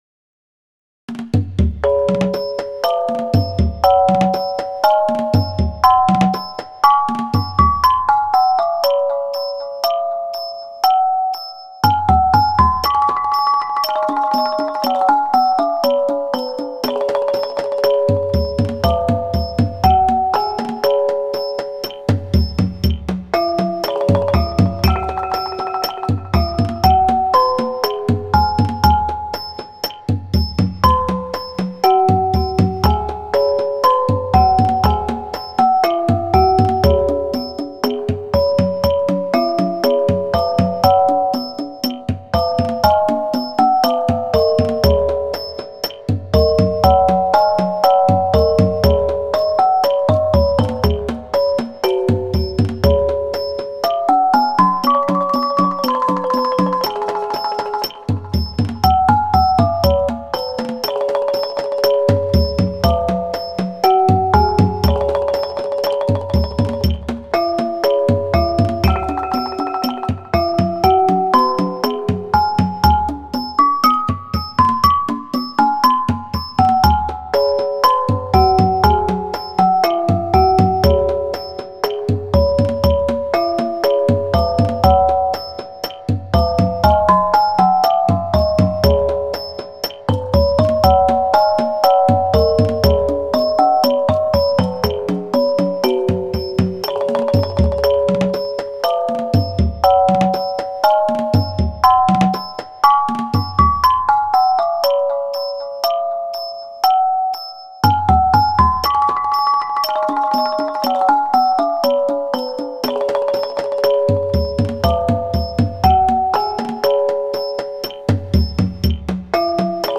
Let us play Thai music